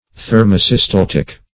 Search Result for " thermosystaltic" : The Collaborative International Dictionary of English v.0.48: Thermosystaltic \Ther`mo*sys*tal"tic\, a. [Thermo- + systaltic.]
thermosystaltic.mp3